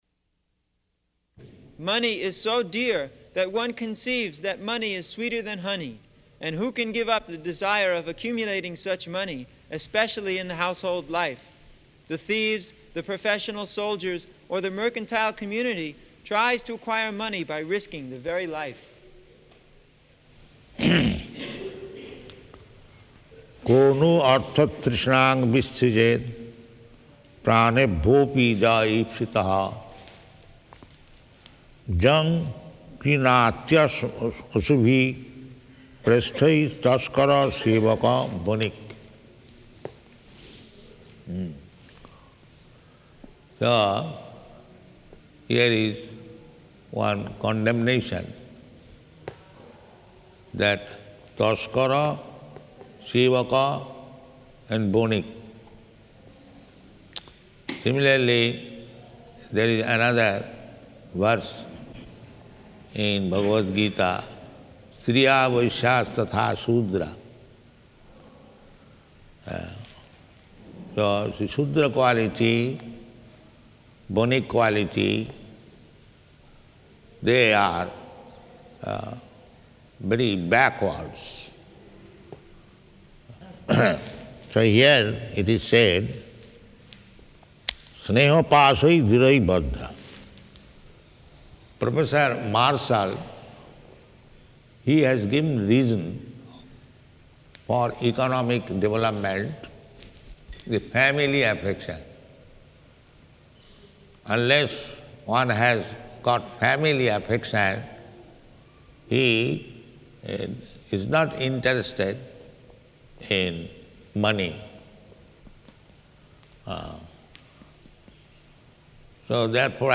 Location: Vṛndāvana
In the Vaikuṇṭha-loka there are thousand times beautiful women and thousand times strong men [talking in background] [aside:] Where is this sound coming?